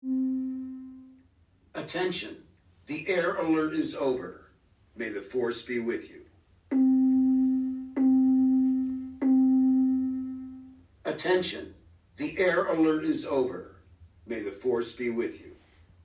At about 5:30 AM we were again awoken with an announcement that penetrated our room, telling us all was clear.
air-raid-alert_1-2.mp3